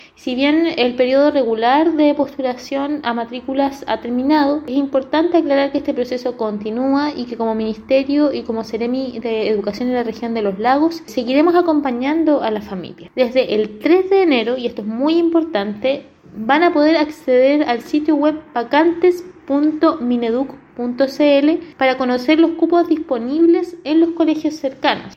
La secretaria regional ministerial del Mineduc sostuvo que siempre han existido establecimientos que tienen más demanda de cupo disponible.